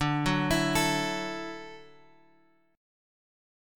Dadd9 chord {x 5 4 2 5 2} chord